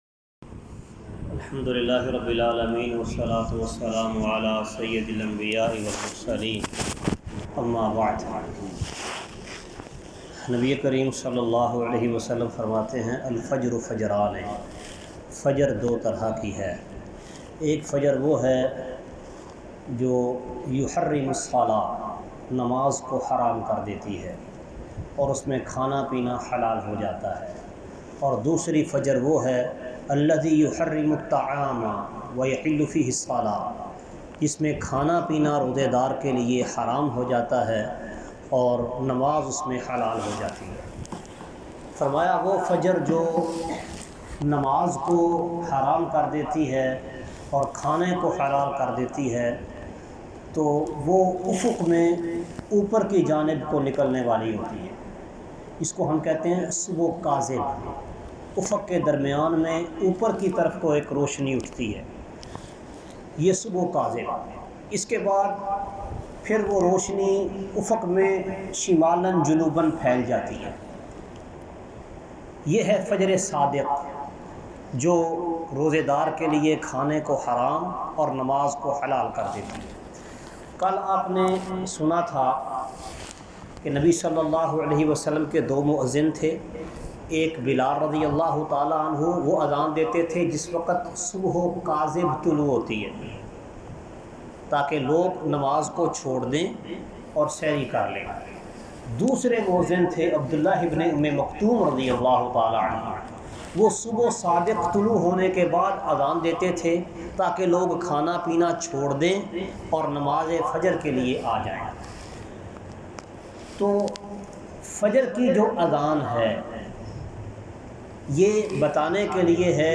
منتہائے سحر درس کا خلاصہ سحری کے وقت کا اختتام آڈیو فائل ڈاؤنلوڈ کریں × الحمد لله رب العالمين، والصلاة والسلام على سيد الأنبياء والمرسلين، أما بعد!